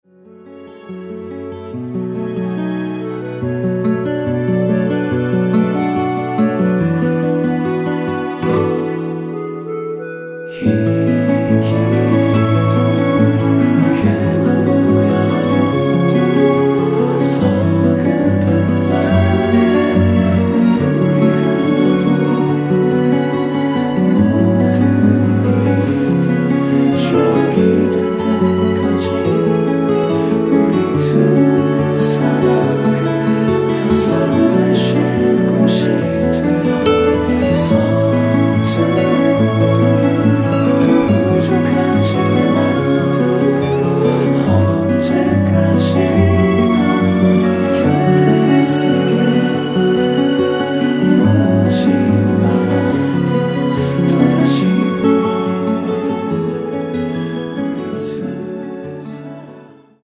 원키 멜로디와 코러스 포함된 MR입니다.(미리듣기 확인)
축가용으로 준비중이에요 음원품질 좋네요!
앞부분30초, 뒷부분30초씩 편집해서 올려 드리고 있습니다.
중간에 음이 끈어지고 다시 나오는 이유는